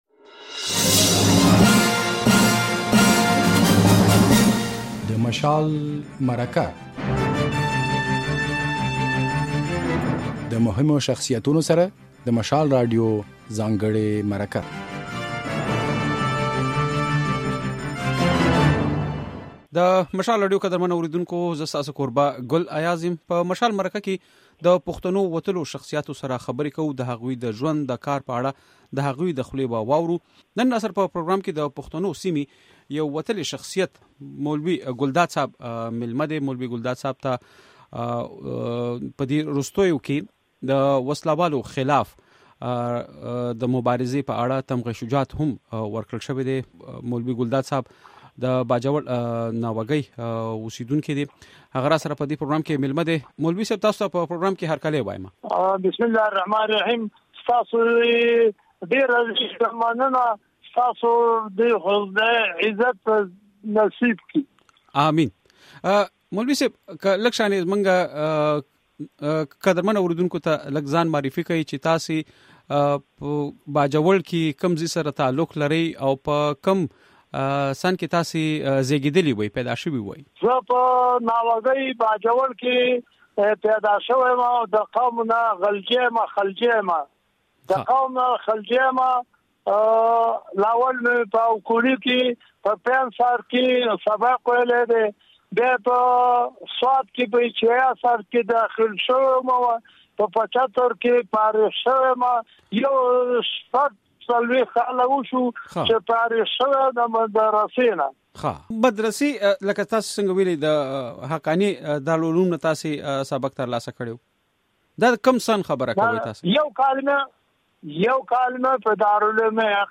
په نننۍ خپرونه کې له نوموړي سره پر دې موضوع مرکه شوې چې ده په ۲۰۰۷ز کال کې په باجوړ کې د وسله والو خلاف مبارزه پيل کړې وه.